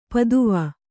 ♪ paduva